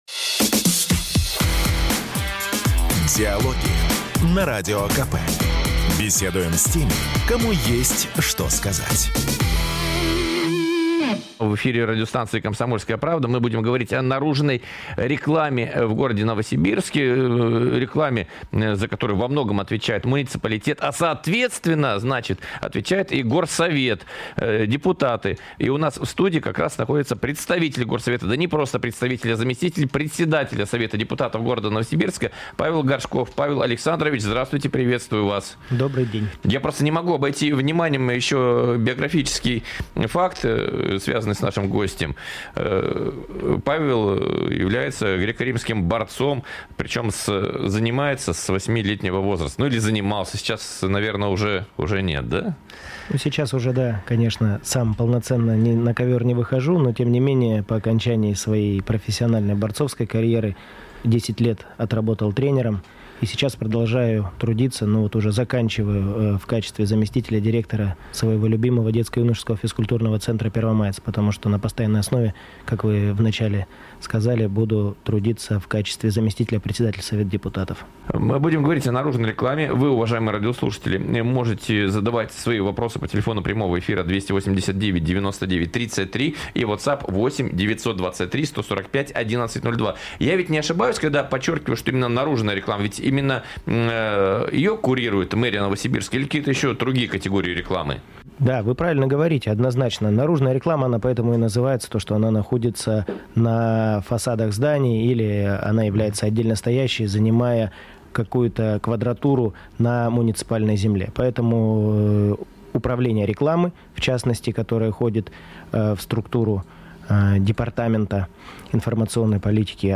08.10.2025 Диалоги-Новосибирск Запись программы, транслированной радио "Комсомольская правда" 08 октября 2025 года Дата: 08.10.2025 Источник информации: радио "Комсомольская правда" Упомянутые депутаты: Горшков Павел Александрович Аудио: Загрузить